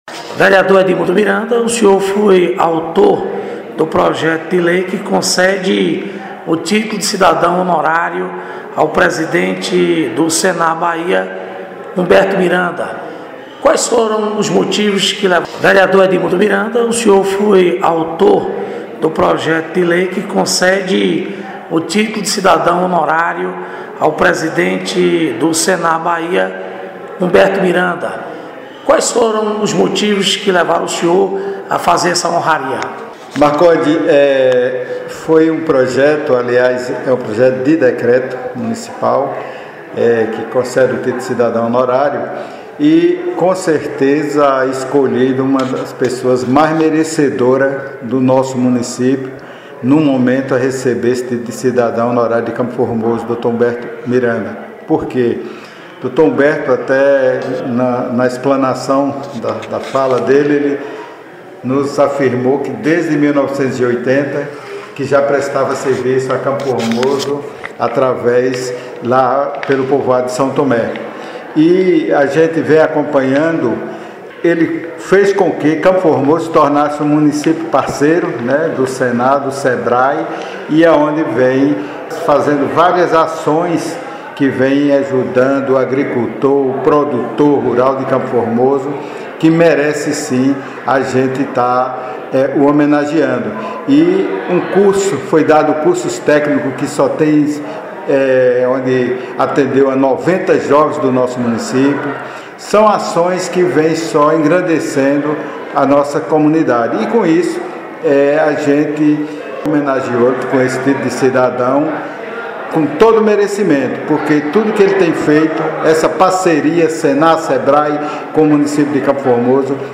Reportagem: Vereadores do município de CFormoso